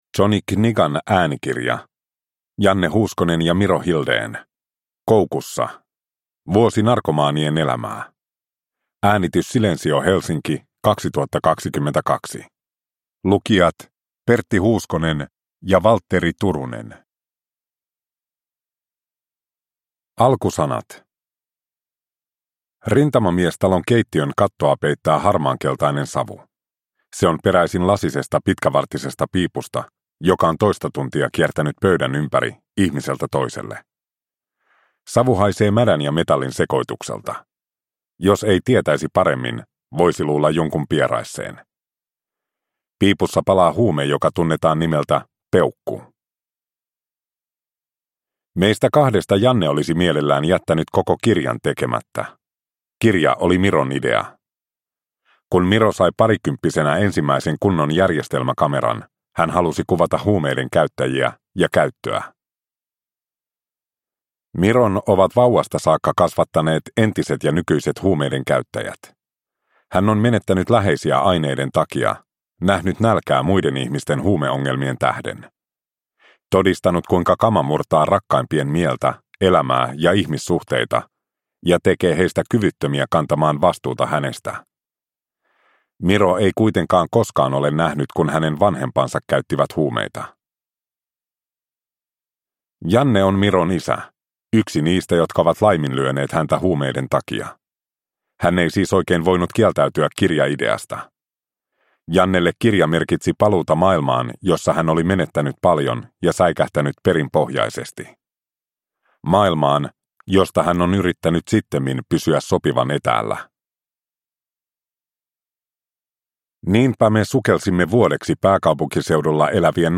Koukussa – Ljudbok – Laddas ner